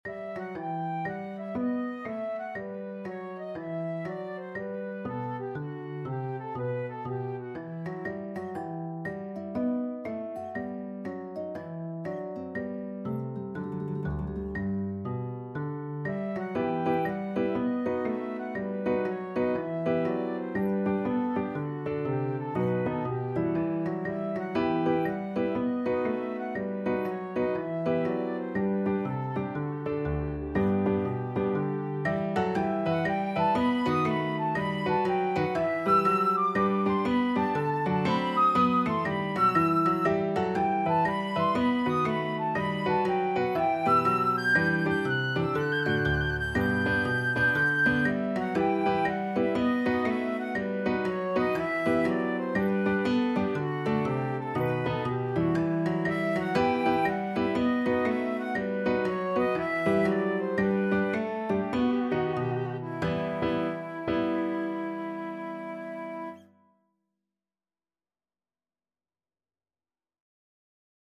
4/4 (View more 4/4 Music)
Quick Swing = c. 120